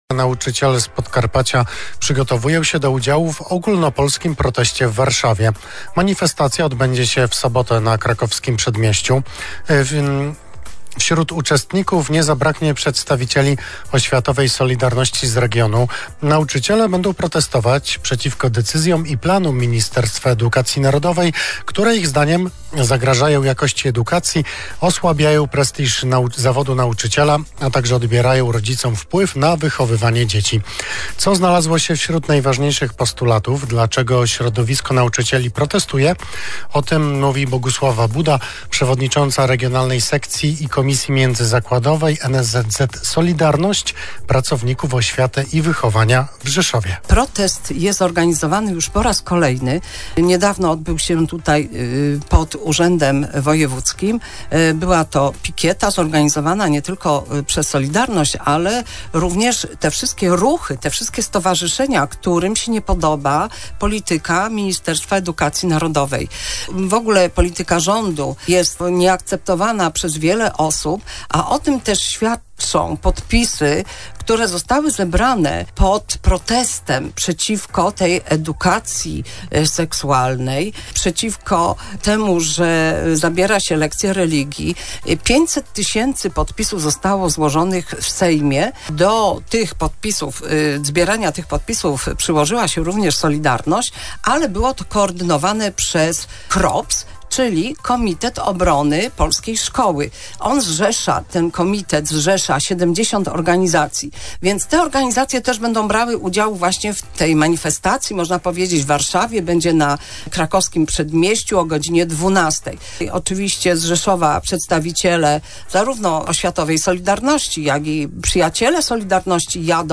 Audycja w Radiu VIA